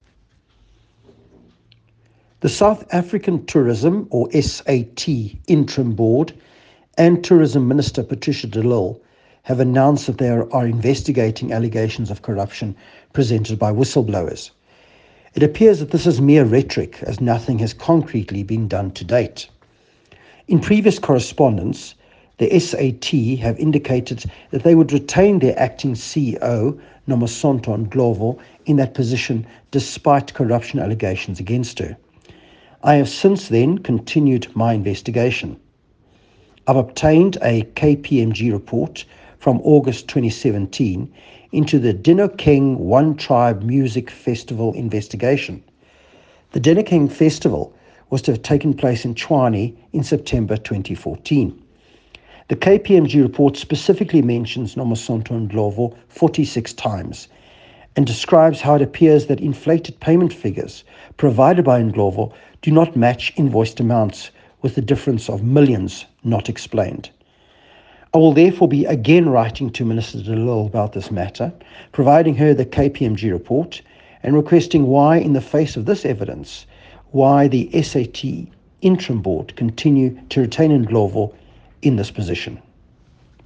Issued by Manny de Freitas MP – DA Shadow Minister of Tourism
soundbite by Manny de Freitas MP.